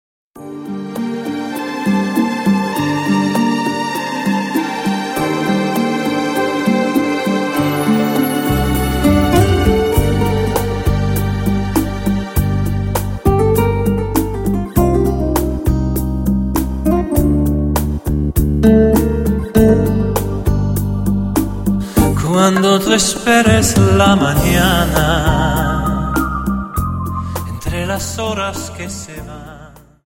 Dance: Rumba